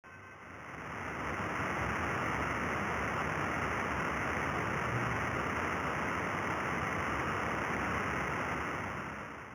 Power Line (60 Hz)
Power_Line.mp3
¶ Power Line (60 Hz) Description: Power line glitches usually look narrow in frequency, are centered around 60 Hz or one of its harmonics, and usually last for about 0.2–0.5 seconds in time.
Cause: Equipment run at 60 Hz alternating current in the US.